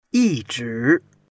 yi1zhi2.mp3